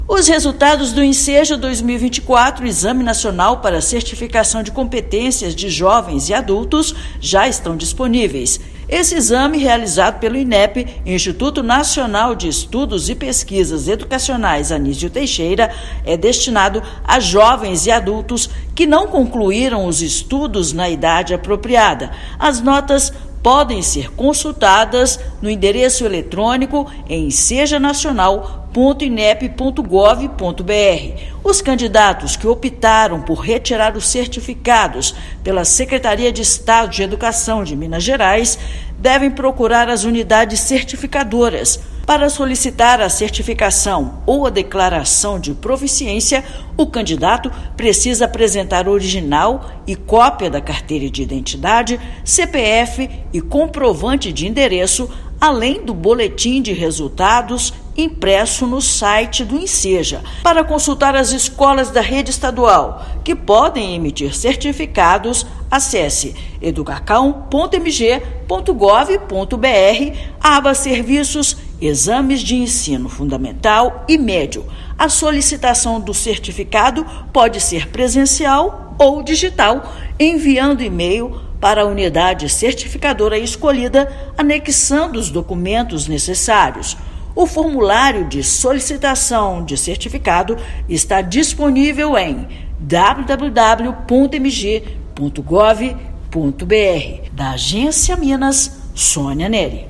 Jovens e adultos que realizaram o exame podem obter o documento por meio de Unidades Certificadoras credenciadas no estado. Ouça matéria de rádio.